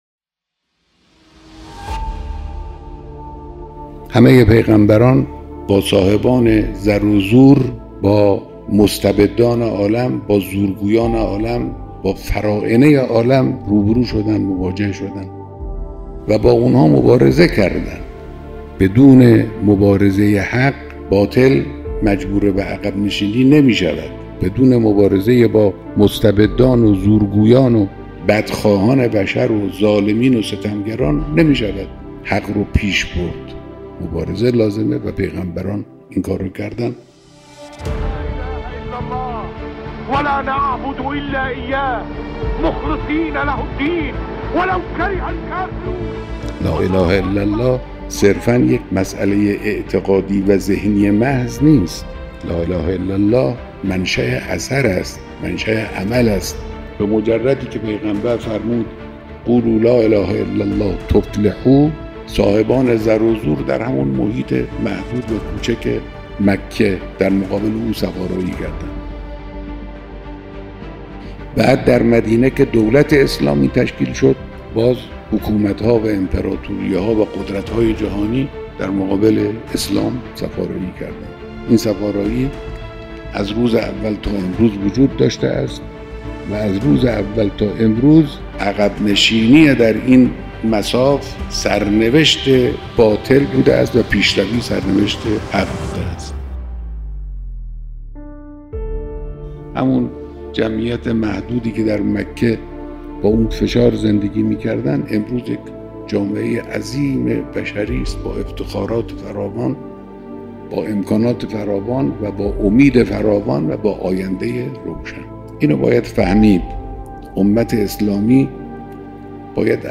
صداهنگ